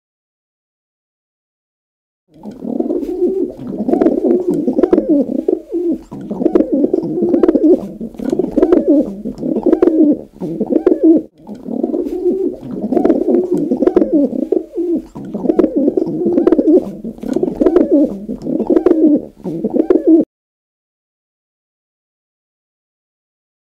دانلود آهنگ کبوتر از افکت صوتی انسان و موجودات زنده
دانلود صدای کبوتر از ساعد نیوز با لینک مستقیم و کیفیت بالا
جلوه های صوتی